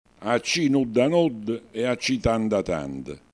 Forma cantilenata per esprimere totale  dissenso alle massime ingiustizie. Si pronunciava, ad esempio,  quando a vantaggio di chi gi? era provvisto di tutto si aggiungevano vincite o regalie.